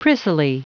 Prononciation du mot prissily en anglais (fichier audio)
Prononciation du mot : prissily